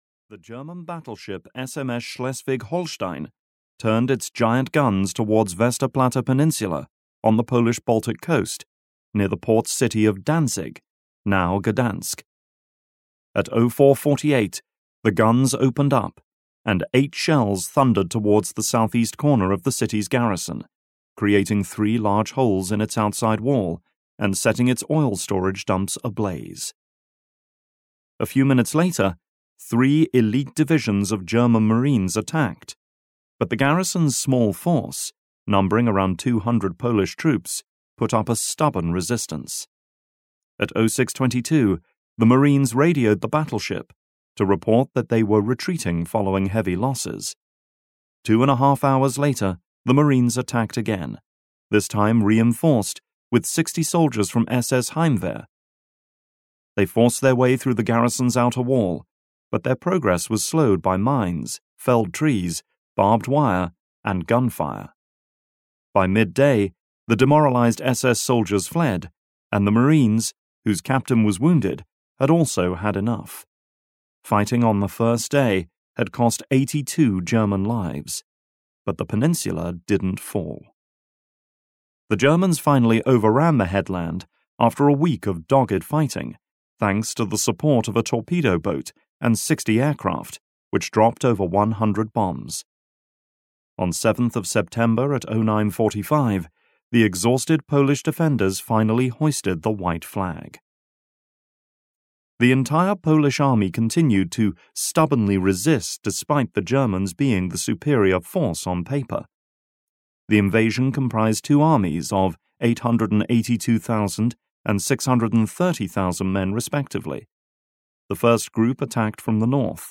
Blitzkrieg (EN) audiokniha
Ukázka z knihy